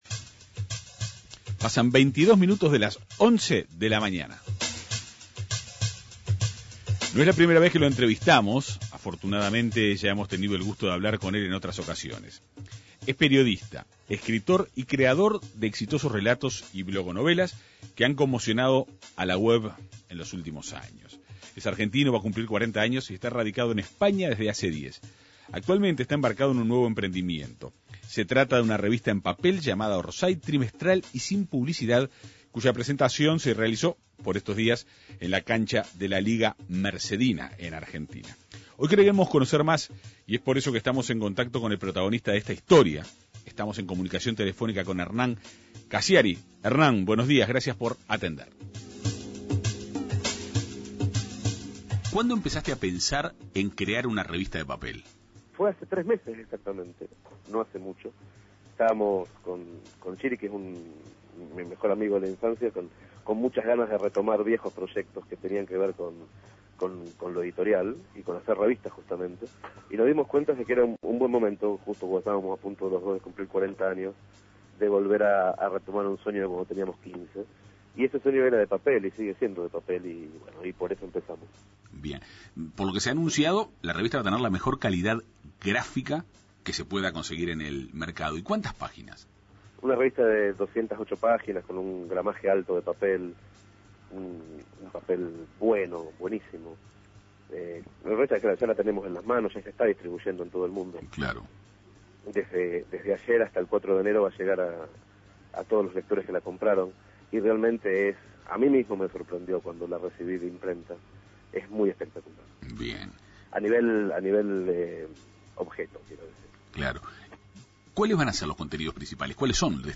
Hernán Casciari, periodista, escritor y creador de blogonovelas y relatos con gran impacto en internet, expuso su nuevo proyecto: una revista, llamada "Orsai", impresa en papel y carente de publicidad. La publicación, sin poseer una línea editorial definida, constará de artículos de periodismo narrativo, crónicas de investigación periodística y literatura. La Segunda Mañana de En Perspectiva conversó con Casciari.